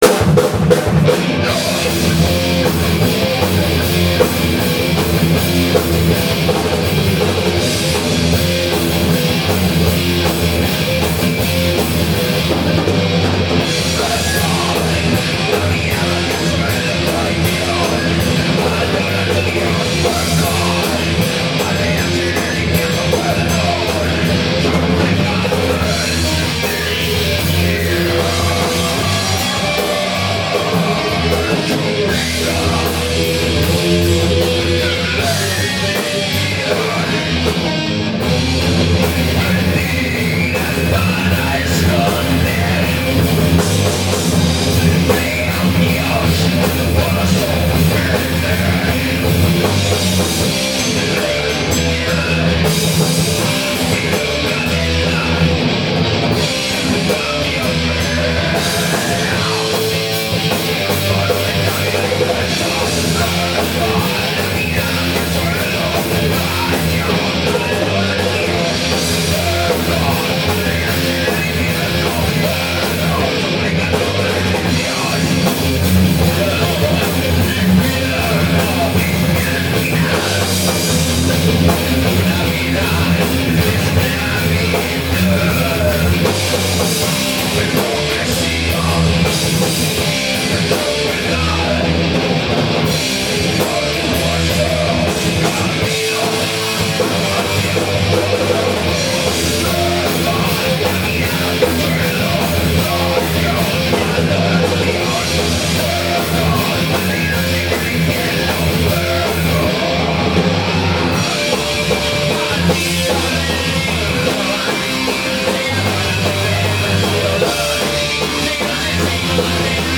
Voz
Guitarra
Batería